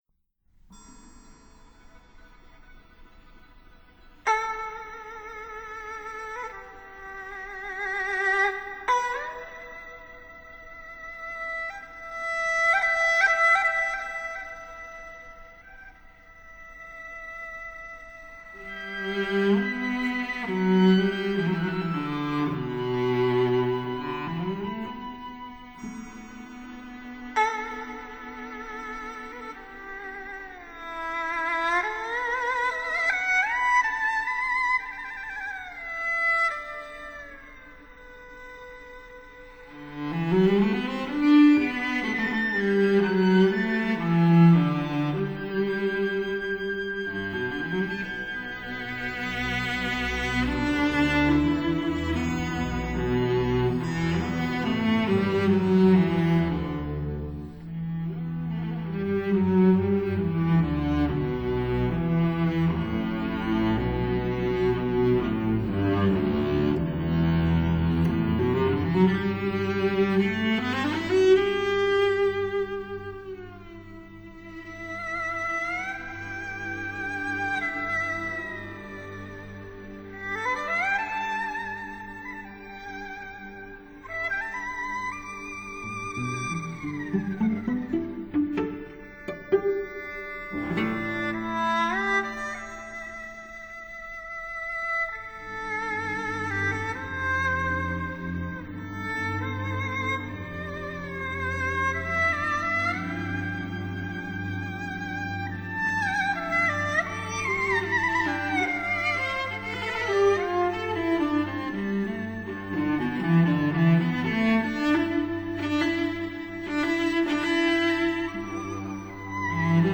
erhu
cello
piccolo trumpet
flugelhorn